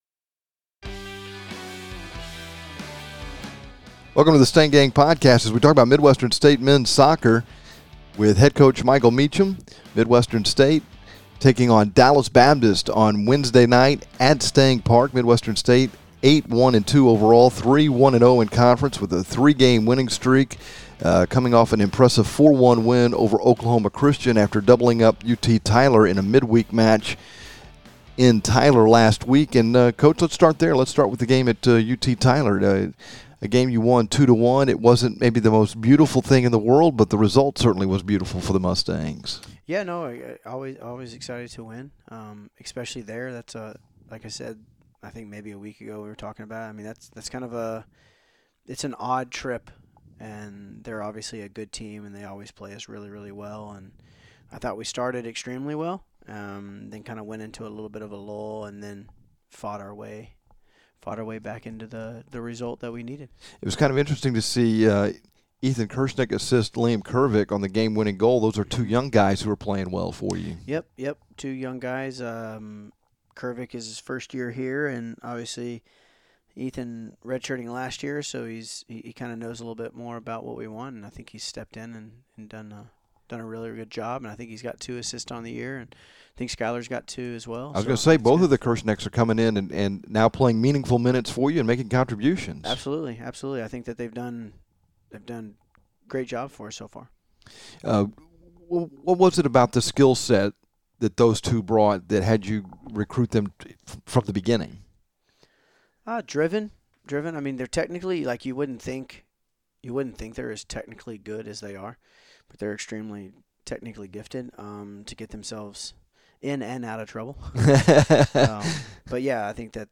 This week's Stang Gang Podcast features complete coverage of Midwestern State Men's Soccer and an interview